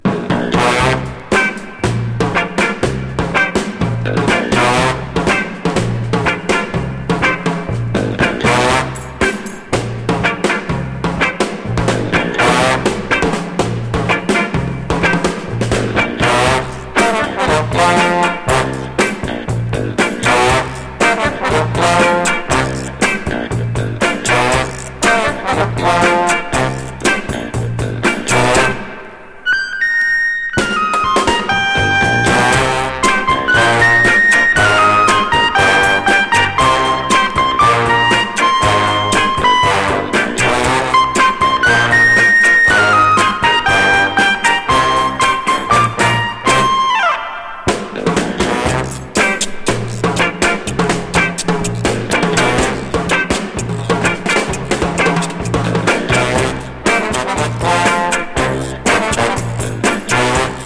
ジャズ・トロンボーン奏者’64年作。